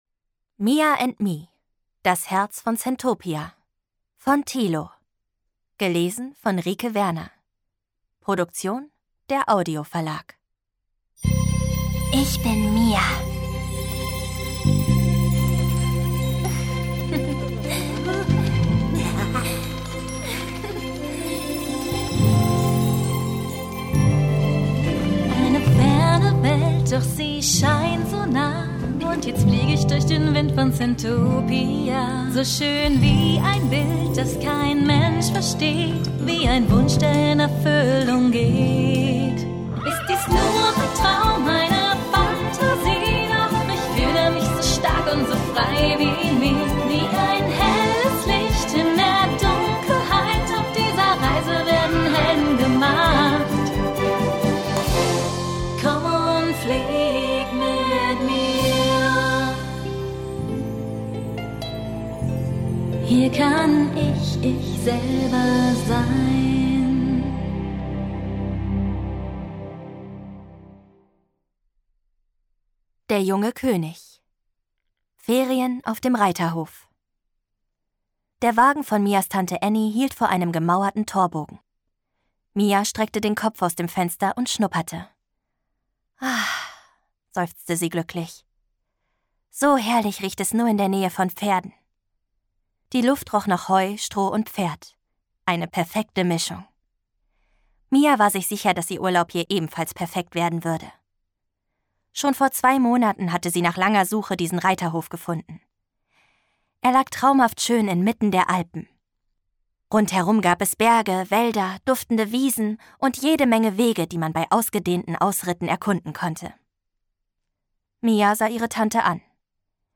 Mia and me: Das Herz von Centopia – Das Hörbuch zur 3. Staffel Lesung mit Musik